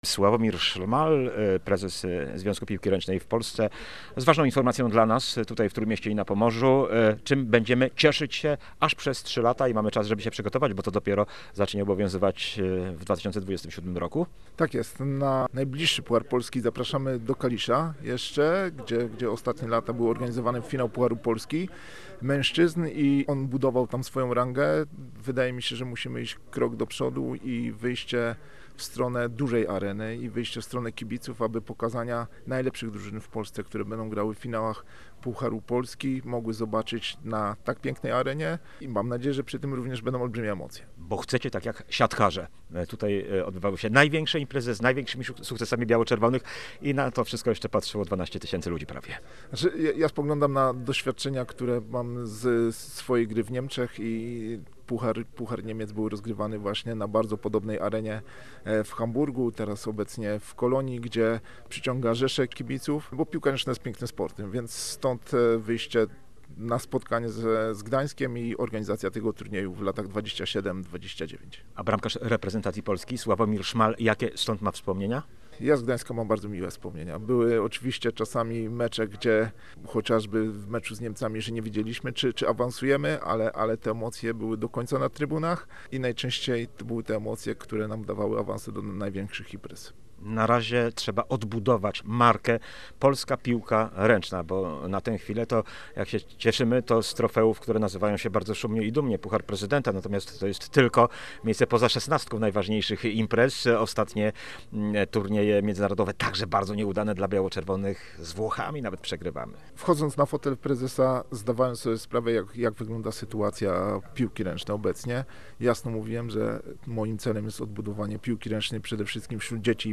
Od przyszłego roku rozgrywki o Puchar Polski w piłce ręcznej odbywać się będą nie tylko w nowej formule, ale też w nowym miejscu. Główną areną zmagań o trofeum stanie się Ergo Arena na granicy Gdańska i Sopotu. O powodach tej decyzji i przyszłości polskiego szczypiorniaka porozmawialiśmy z prezesem